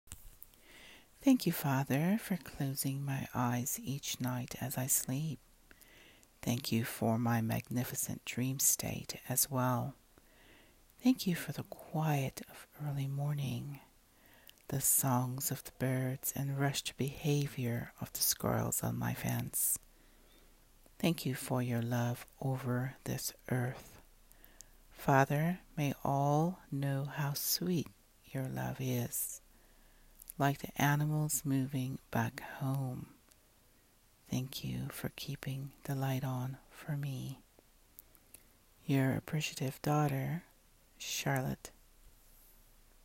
Spoken words: